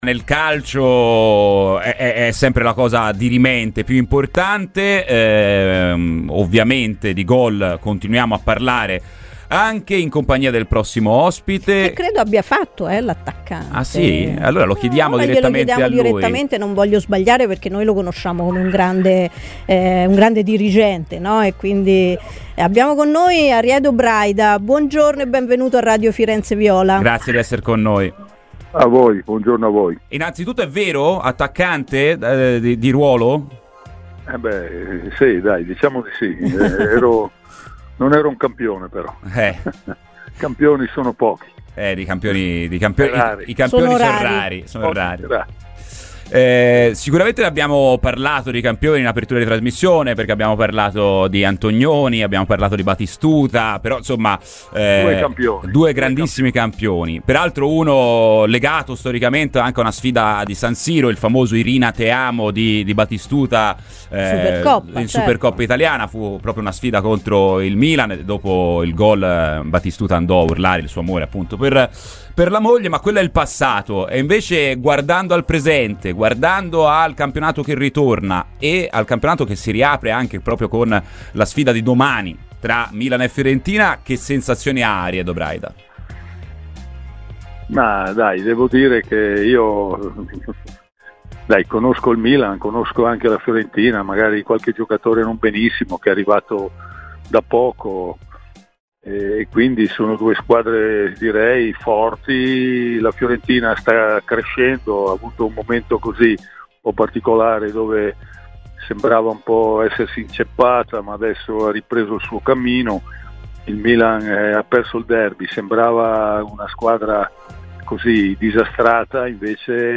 Lex dirigente del Milan Ariedo Braida ha parlato oggi a Radio Firenzeviola.